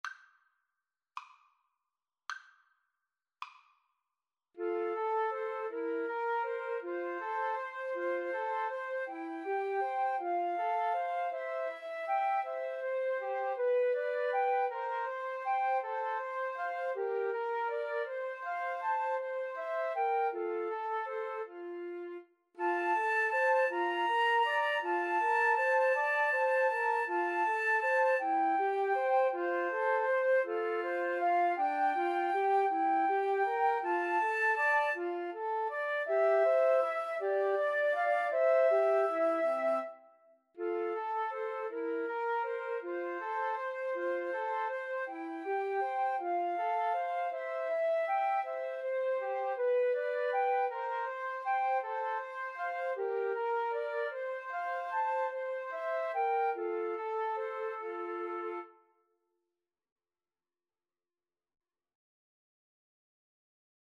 Andante
6/8 (View more 6/8 Music)
Flute Trio  (View more Intermediate Flute Trio Music)